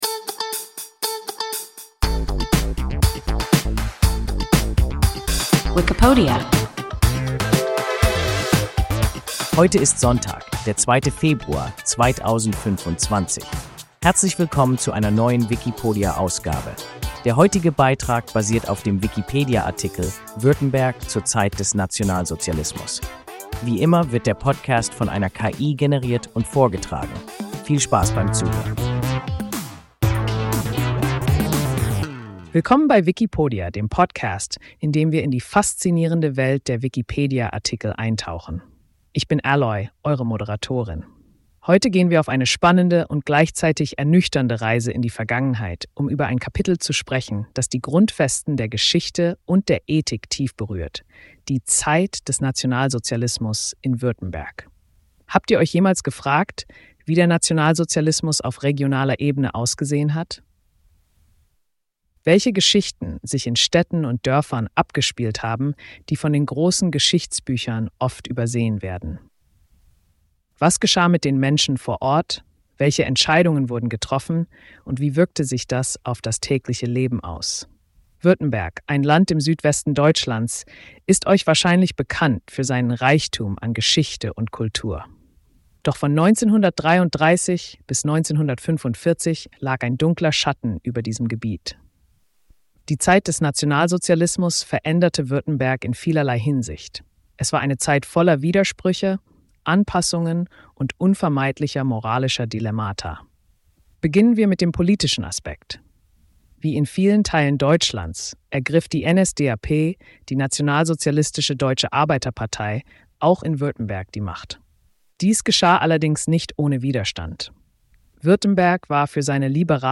Württemberg zur Zeit des Nationalsozialismus – WIKIPODIA – ein KI Podcast